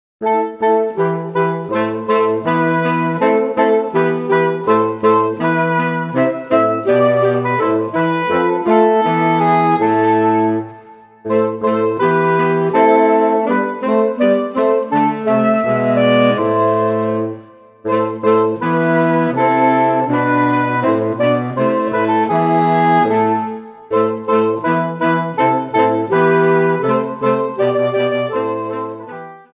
4 Saxophones (SATB)